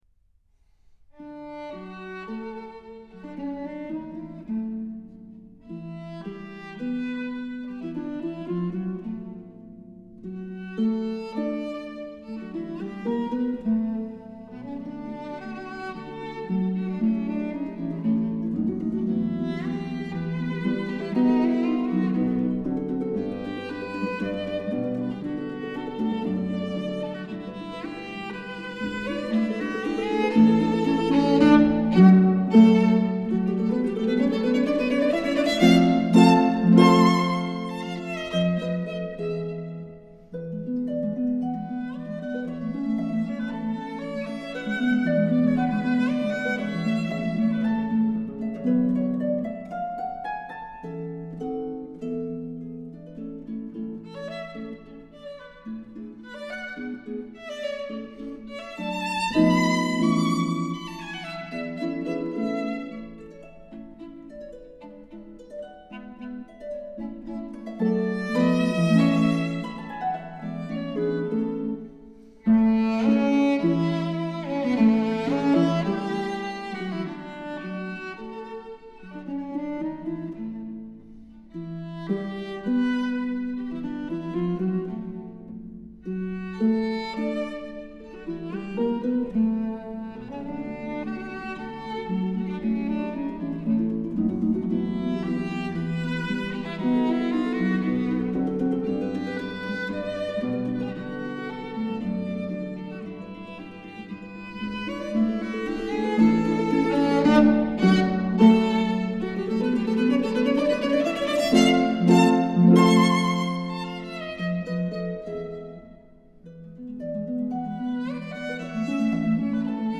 harp
violin
arranged for violin and harp